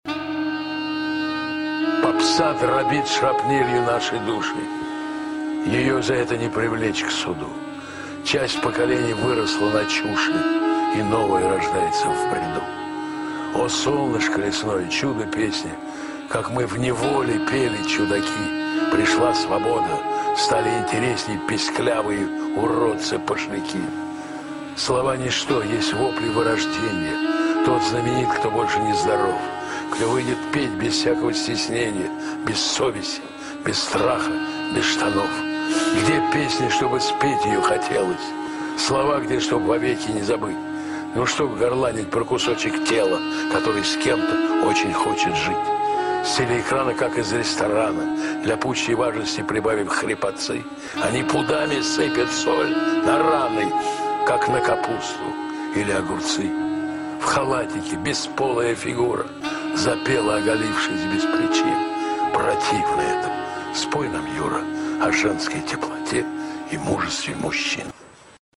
Несколько стихотворений в исполнении автора: